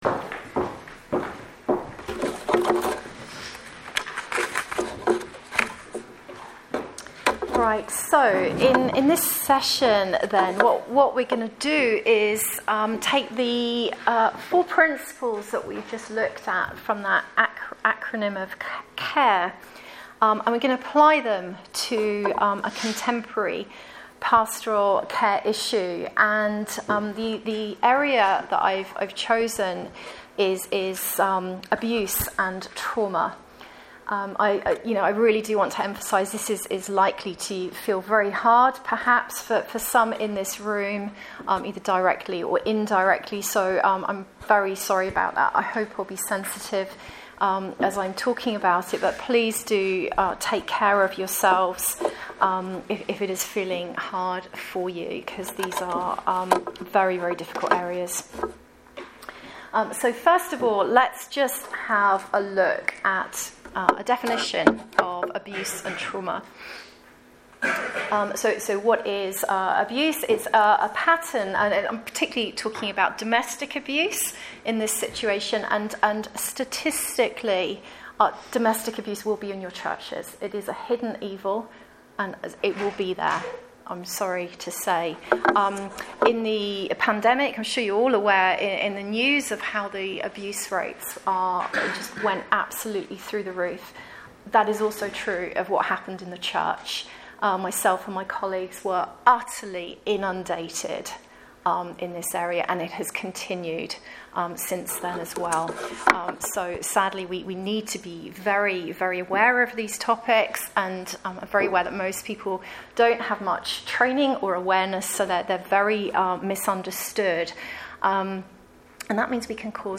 Service Type: Special event